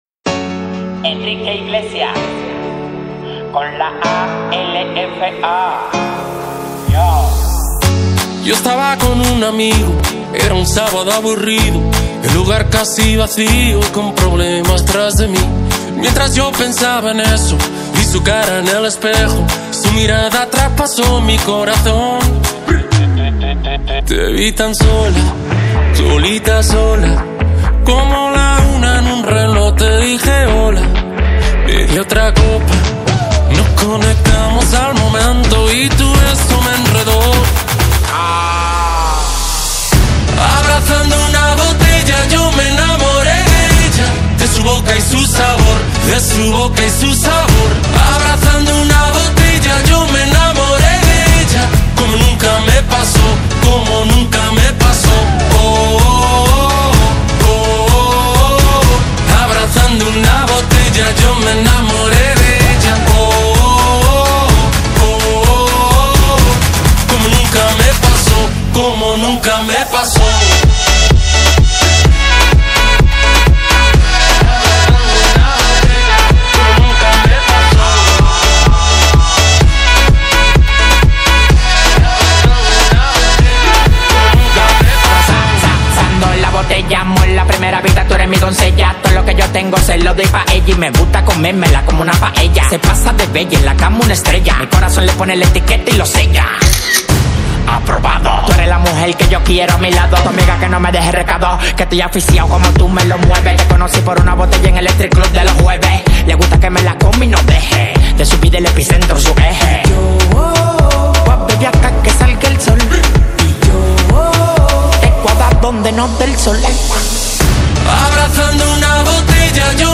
Genre: Latin